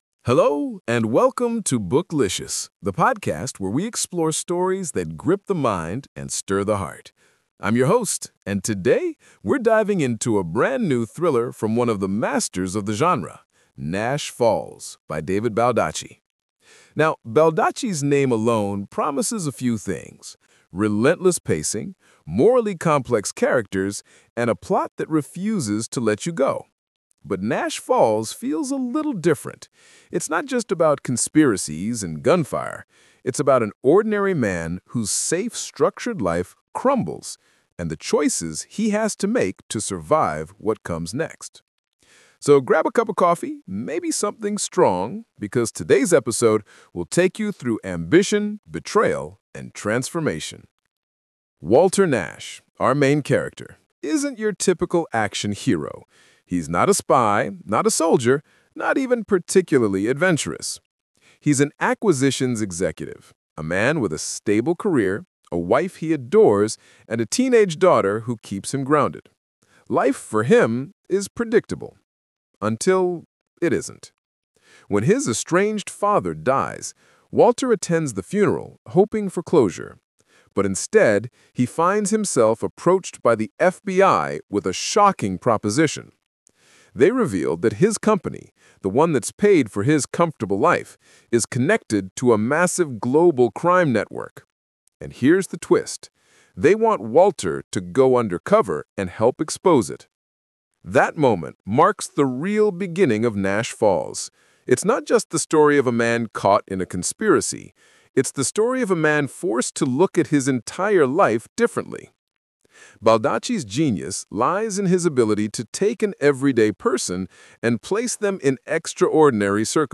Nash Falls: By David Baldacci | Book Review Podcast